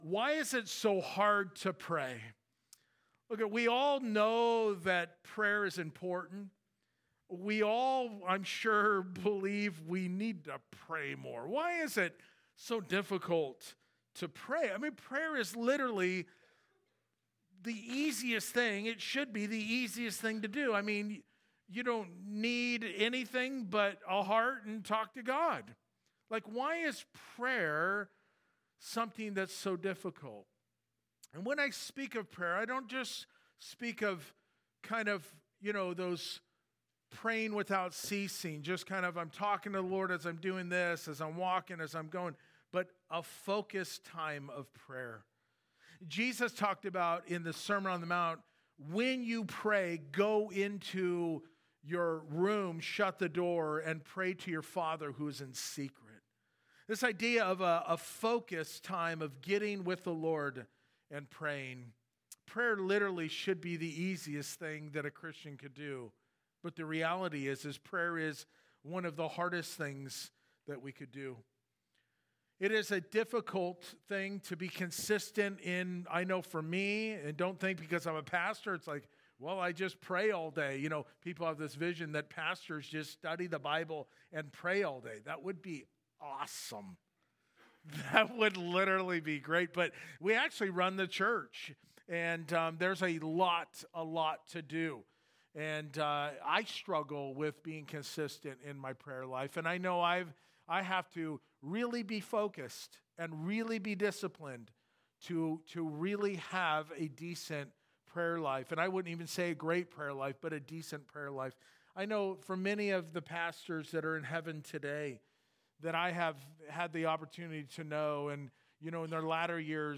Why+Is+It+So+Hard+to+Pray+2nd+Service.mp3